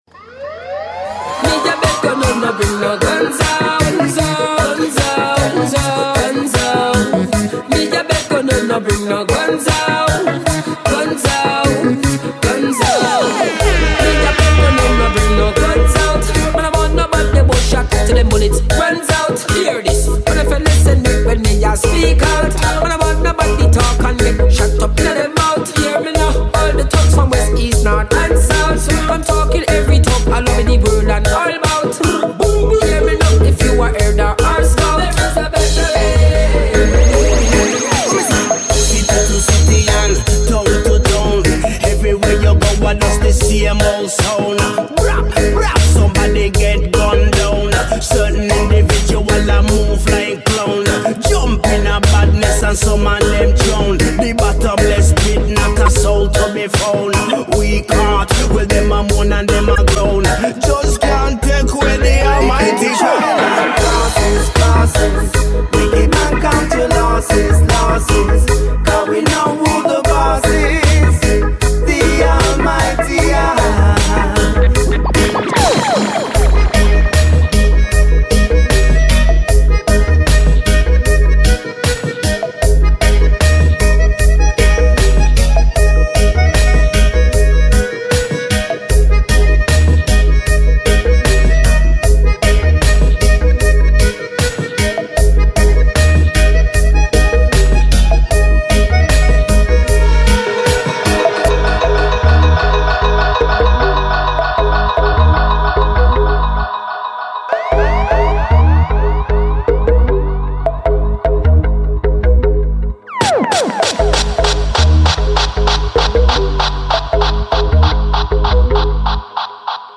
melodica
dubwise storm